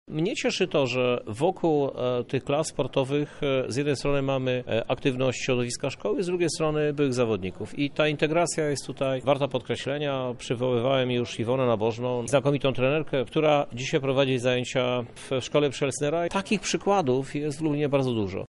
Równie ważna co infrastruktura jest motywowanie młodych do uprawiania sportu – mówi Prezydent Miasta, Krzysztof Żuk W kończącym się roku szkolnym w Lublinie funkcjonowało…